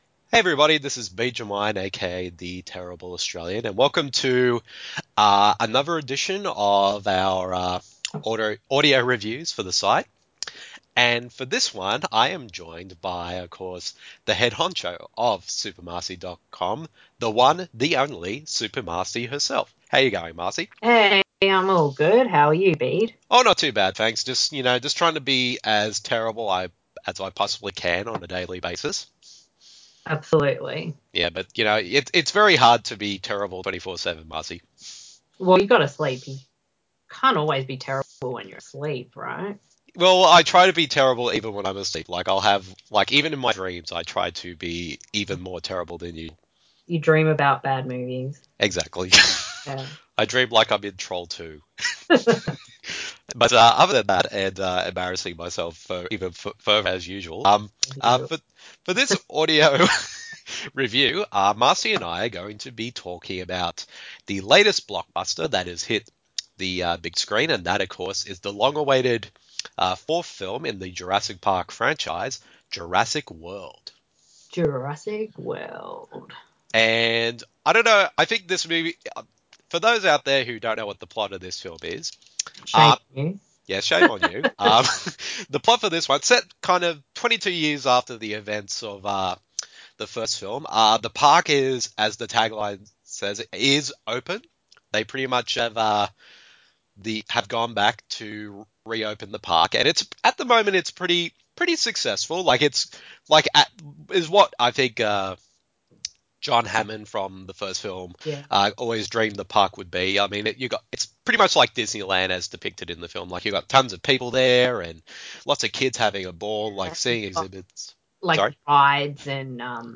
The following review in an audio format, as a back and forth discussion between the two of us.